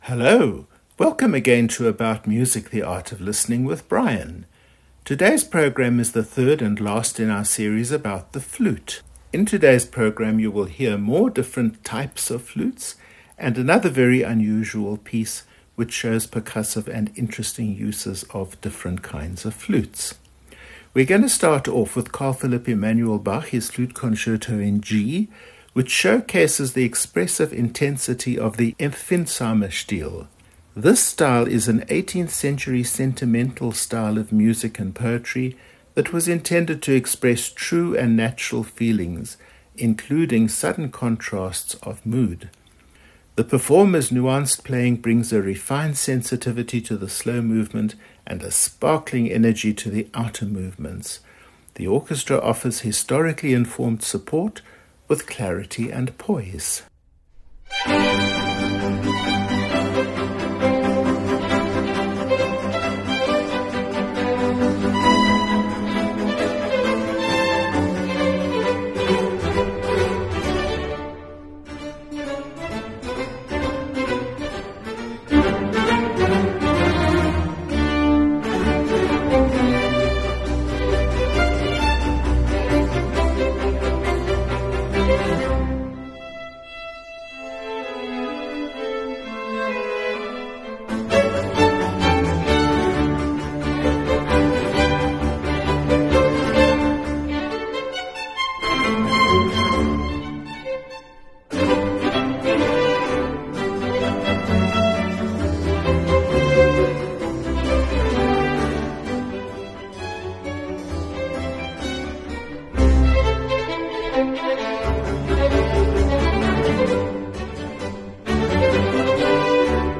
A contemplative work for solo bass or contrabass flute